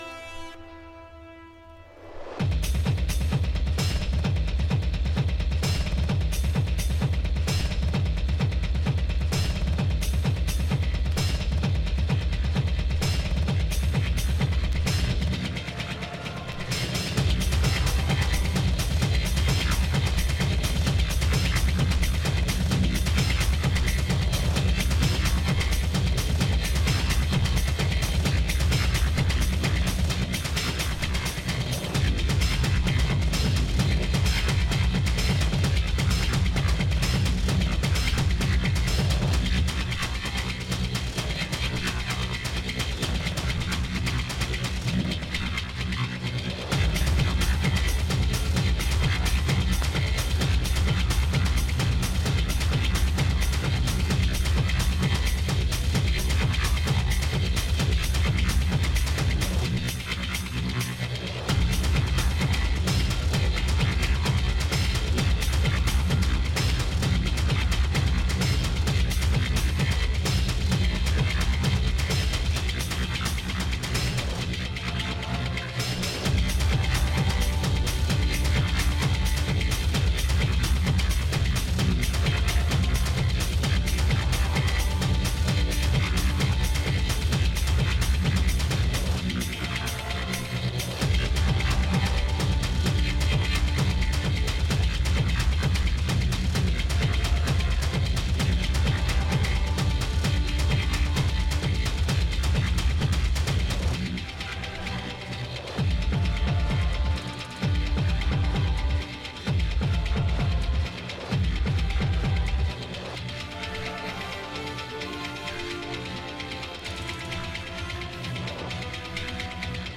EBM/Industrial, Techno